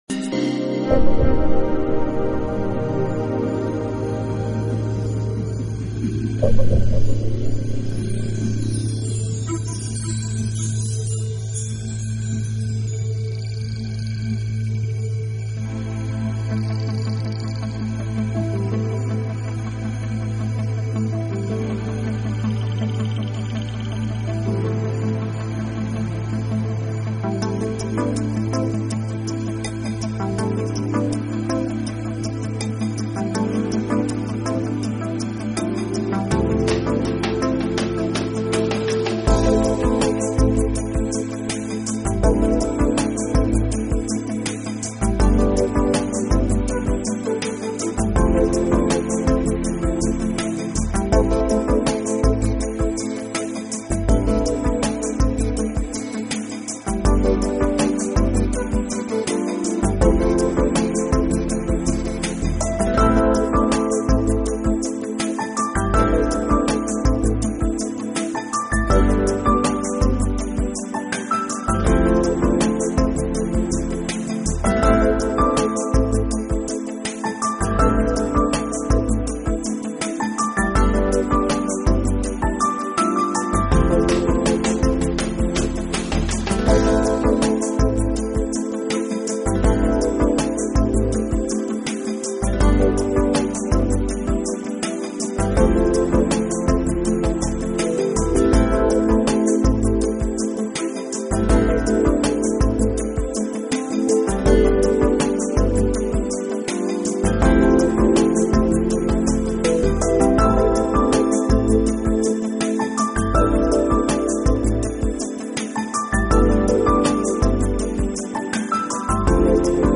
【情调爵士】
Genre: Smooth Jazz | Jazz